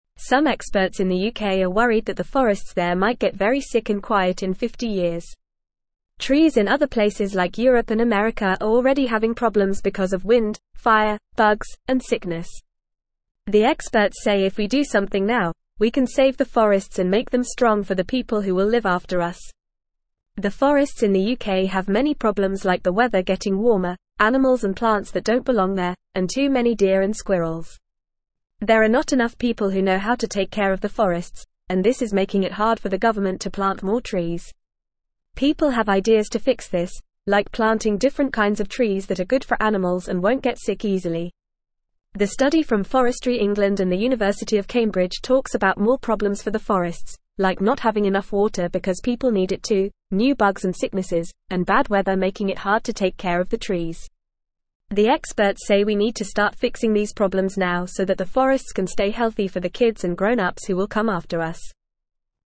Fast
English-Newsroom-Lower-Intermediate-FAST-Reading-Smart-People-Want-to-Save-the-Forests.mp3